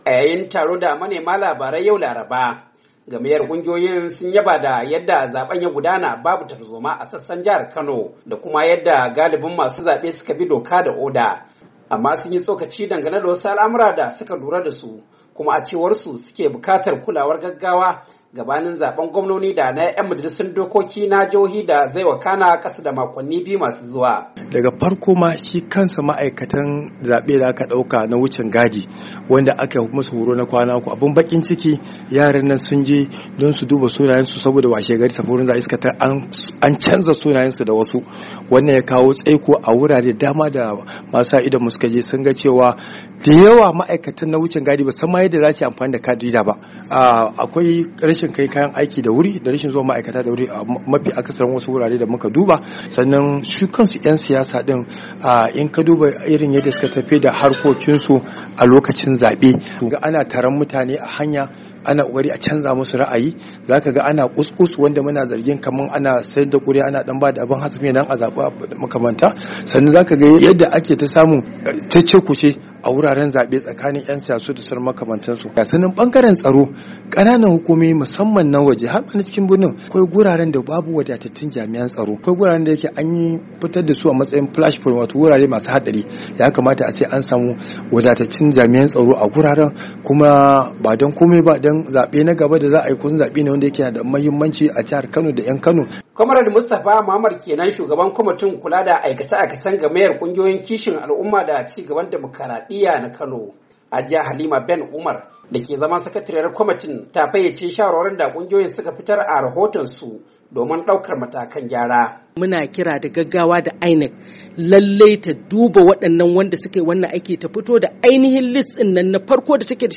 A wani taron manema labarai yau laraba, gamayyar kungiyoyin sun yaba da yadda zaben ya gudana babu tarzoma a sassan jihar Kano da kuma yadda galibin masu zabe suka bi doka da oda, amma sunyi tsokaci dangane da wasu al’amura da suka lura dasu kuma suke bukatar kulawar gaggawa gabanin zaben gwamnoni da na ‘yan Majalisun Dokoki na jihohi da zai wakana kasa da makonni biyu masu zuwa.